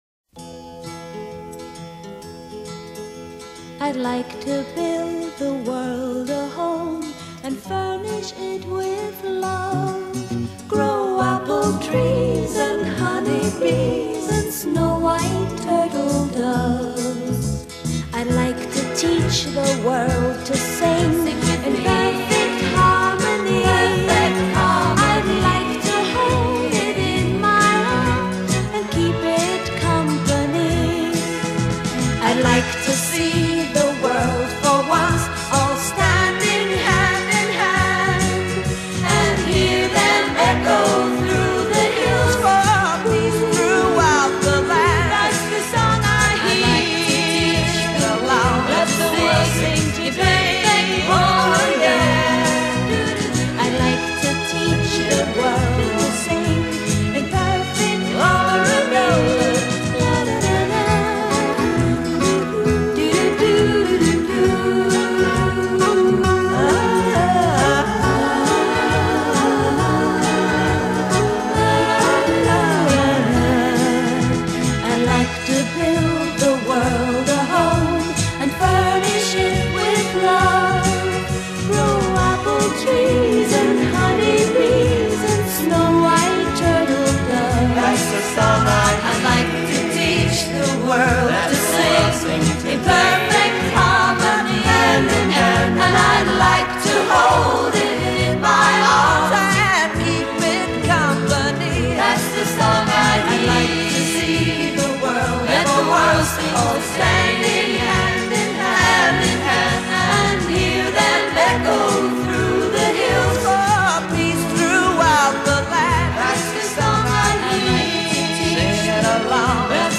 【最清纯的歌声】